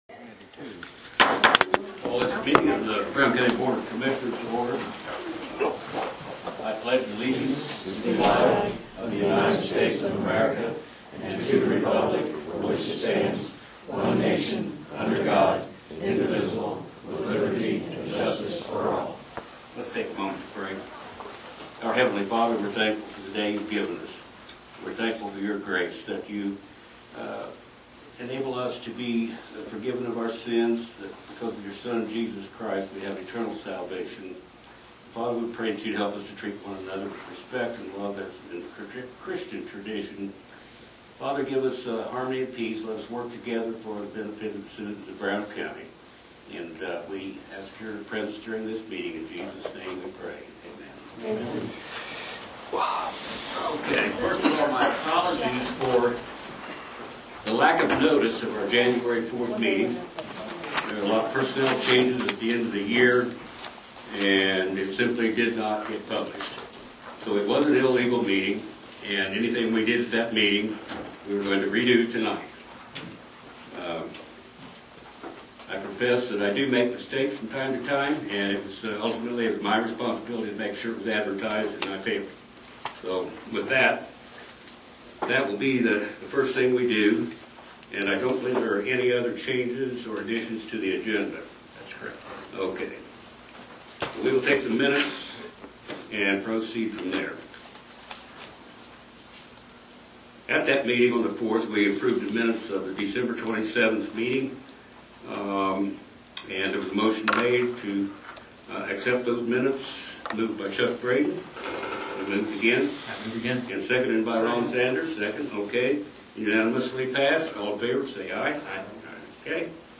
Commissioner Meeting Notes Jan 18, 2023